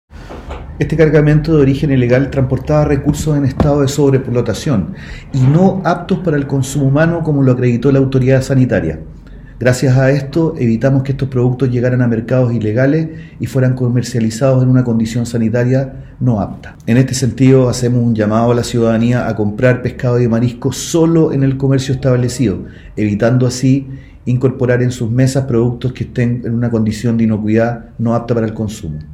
Cristian Hudson, director regional de Sernapesca Los Lagos, se refirió a lo ocurrido: